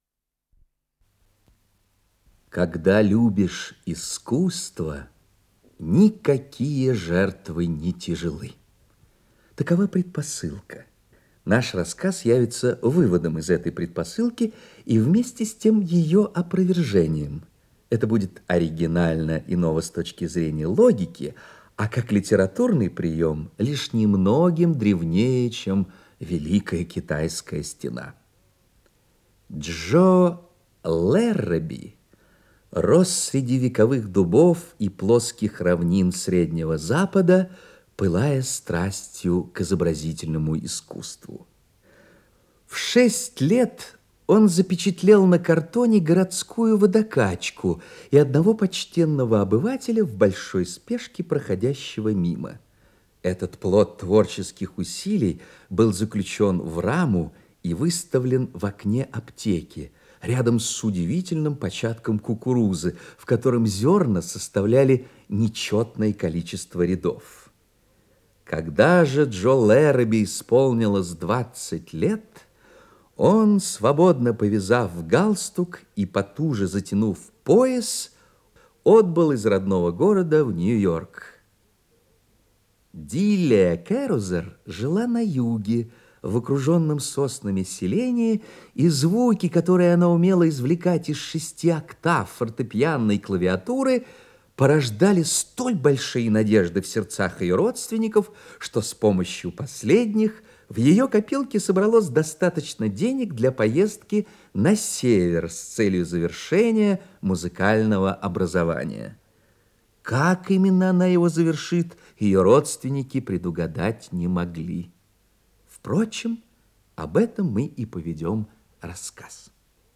Исполнитель: Михаил Державин - чтение